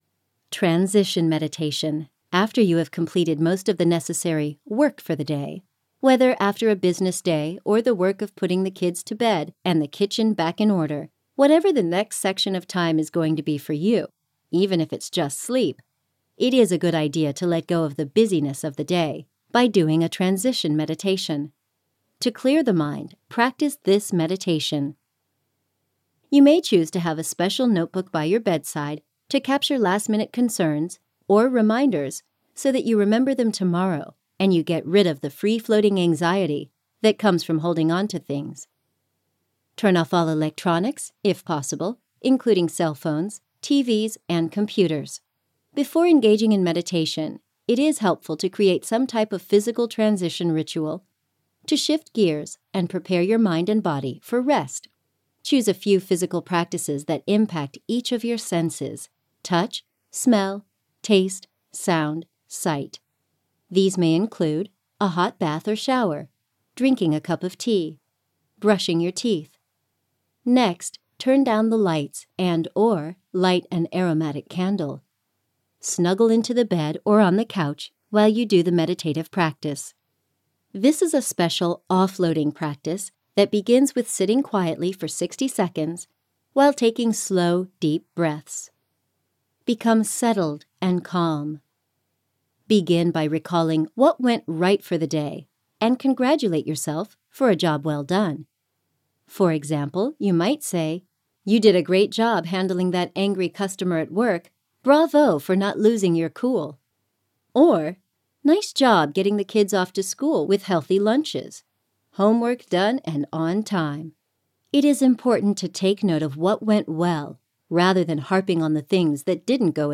Transition Meditation {Guided Audio} to release the stress of the day | Resilience + Self-Love + Holistic Healing of Trauma & Adverse Childhood Experiences
Meditation